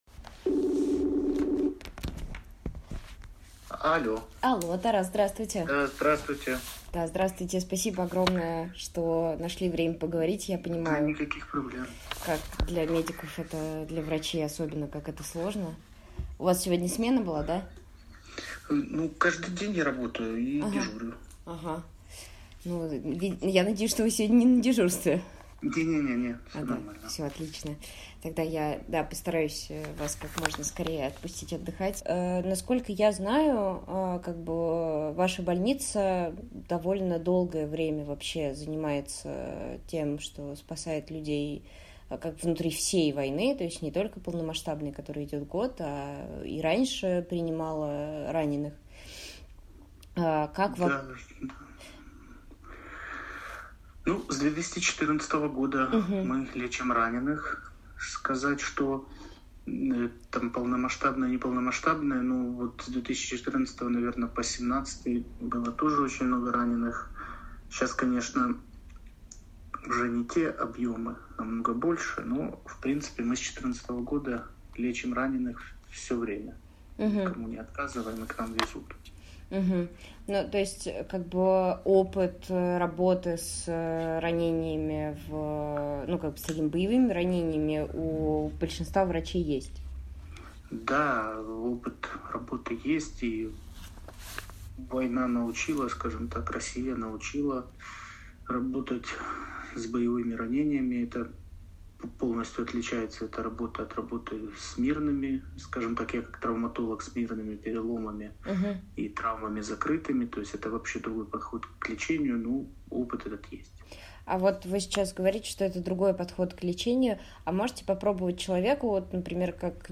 Интервью Предыдущая Следующая Часть 1